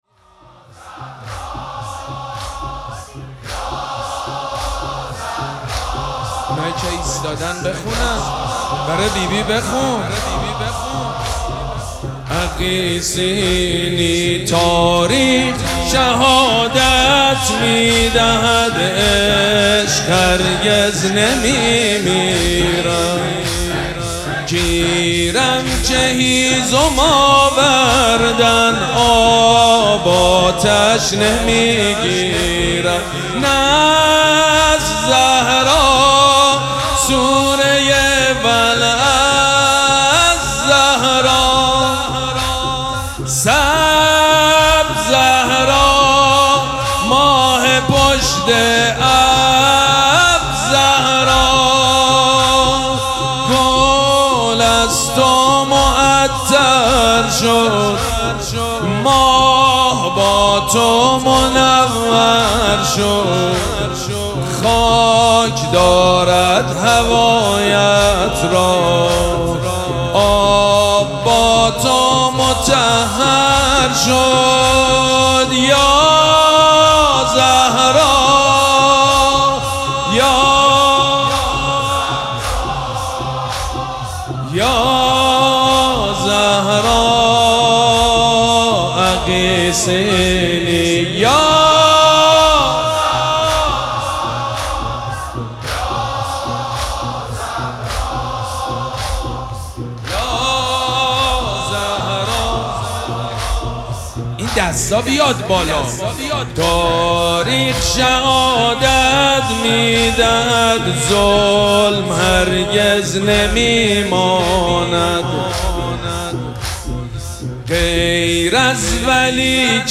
شب دوم مراسم عزاداری دهه دوم فاطمیه ۱۴۴۶
حاج سید مجید بنی فاطمه